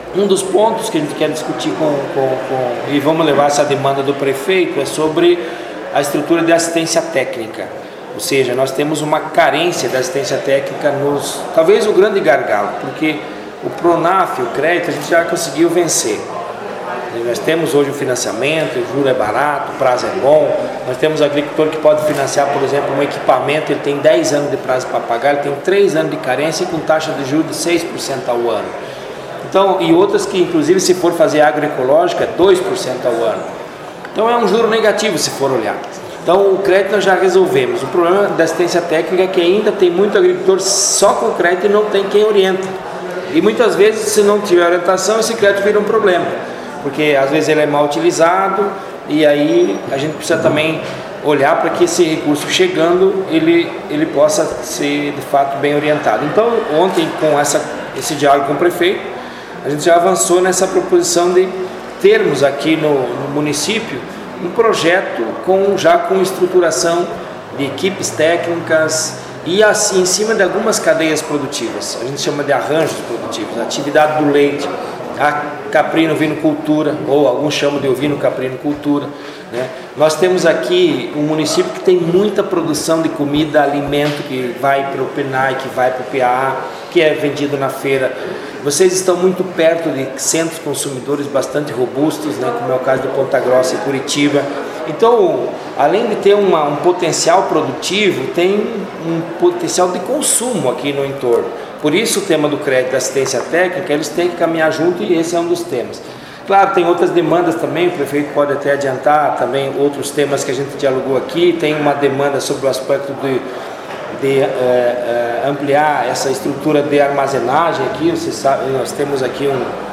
O Secretário Nacional da Agricultura Familiar do Ministério do Desenvolvimento Agrário, Vanderlei Zimmer, conversou com o Jornal da Cruzeiro antes da abertura dos eventos, destacando um dos anúncios que fez voltados para a agricultura familiar da região, que terá impacto em Palmeira, com investimento inicial de R$ 4 milhões para assistência à transição de sistema de produção.